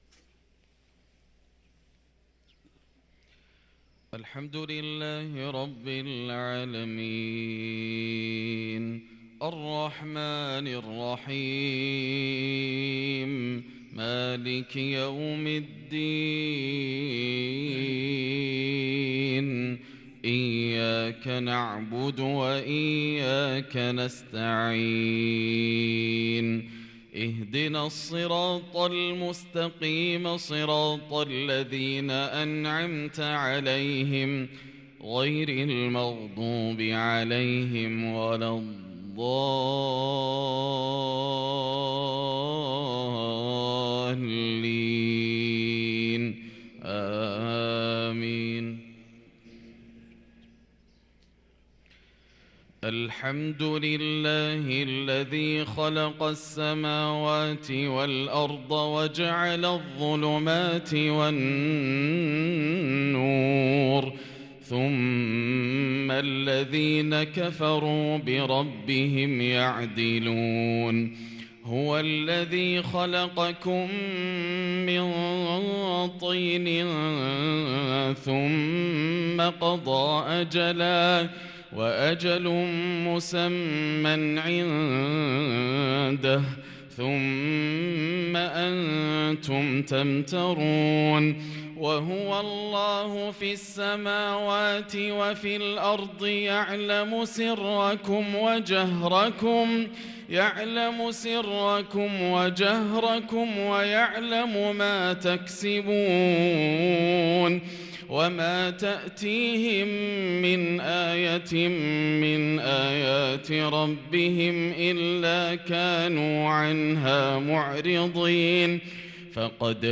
صلاة الفجر للقارئ ياسر الدوسري 2 جمادي الآخر 1444 هـ
تِلَاوَات الْحَرَمَيْن .